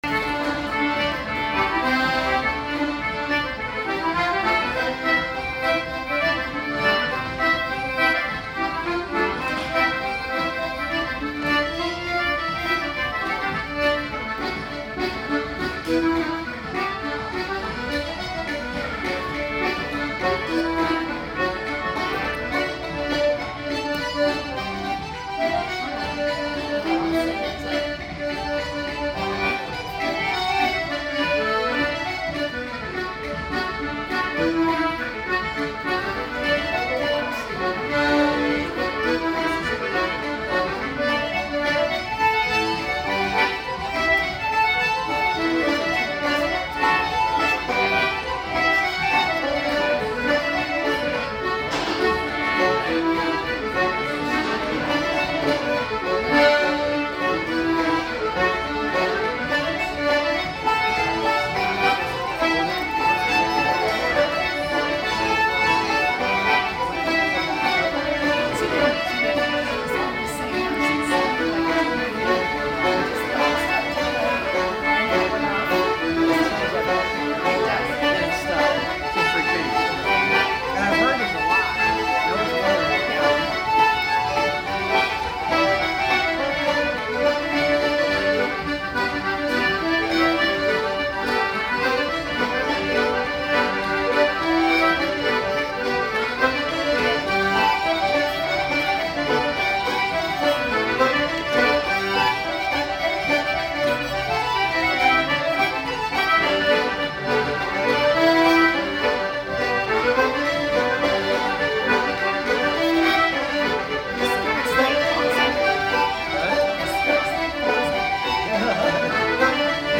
North American Comhaltas convention in Orlando
Many sessions started out small each night, in an one of the alcoves outside the hotel.
And the music continued well after midnight. At times there were about 50 musicians.